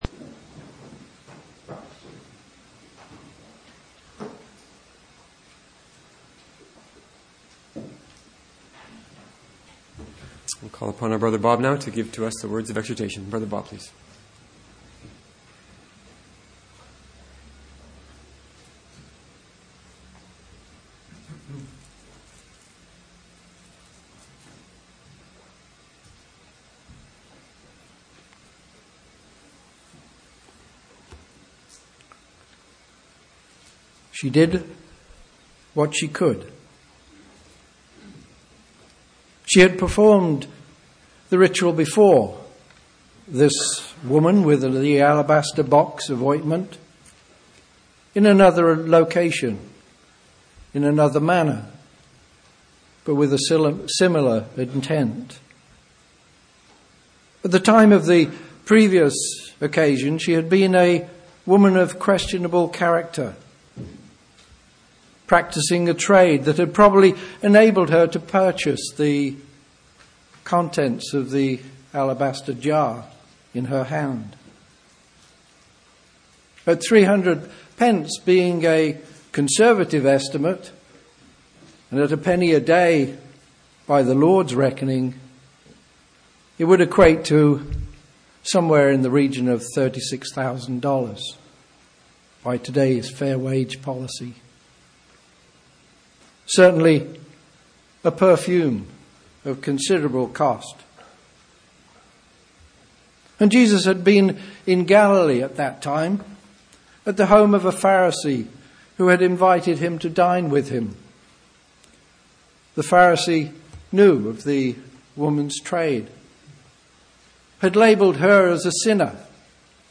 Exhortation 02-18-18